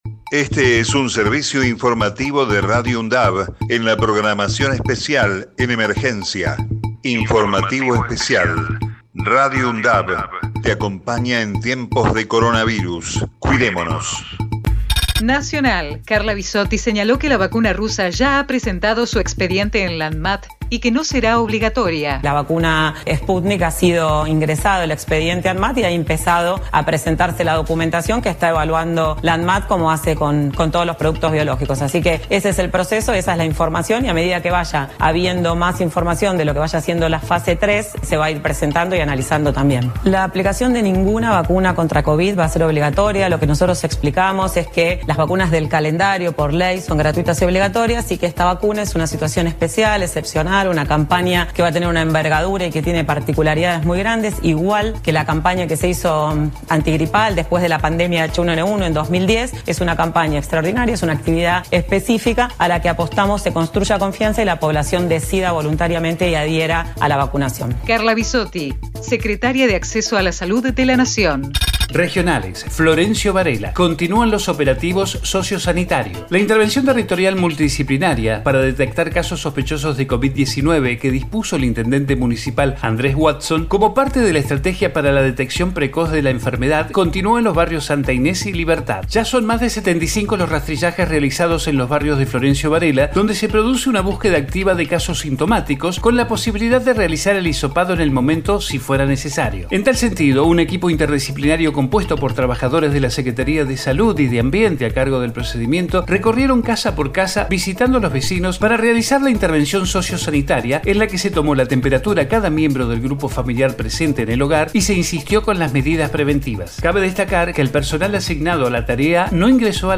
COVID-19 Informativo en emergencia 06 de noviembre 2020 Texto de la nota: Este es un servicio informativo de Radio UNDAV en la programación especial en emergencia.